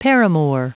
Prononciation du mot paramour en anglais (fichier audio)
Prononciation du mot : paramour